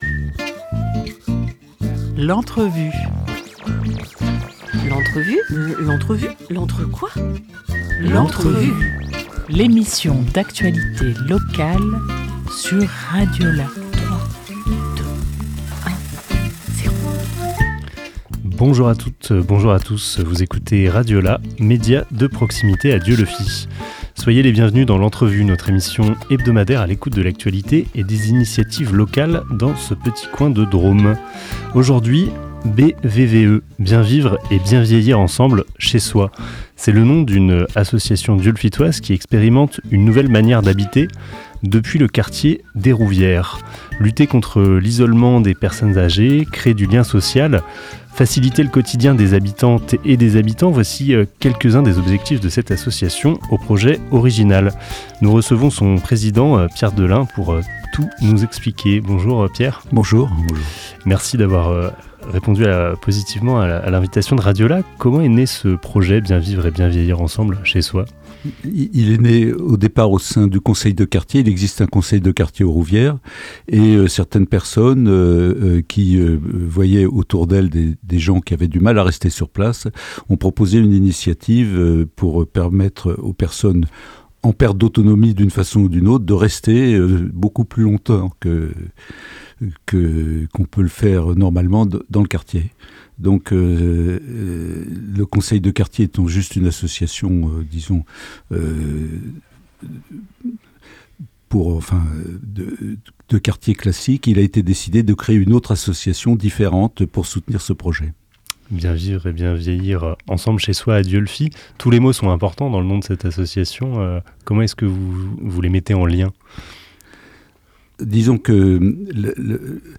5 novembre 2024 11:33 | Interview